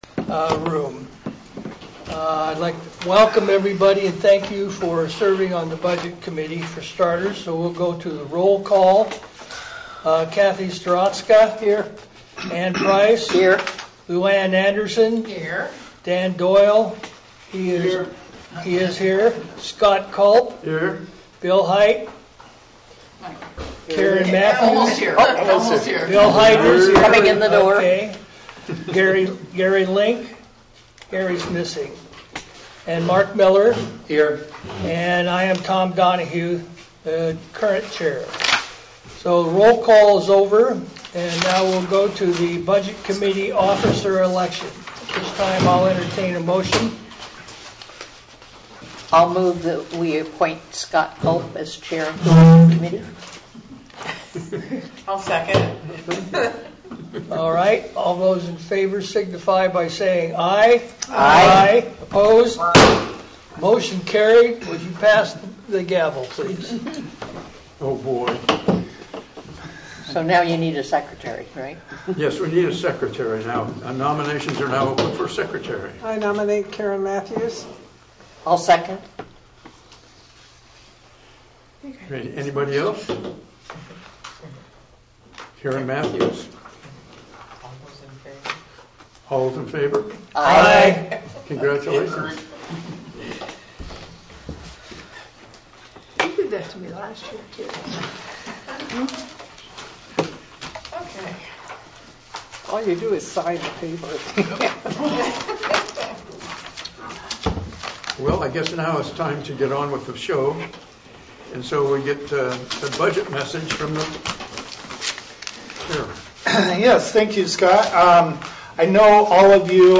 Budget Meeting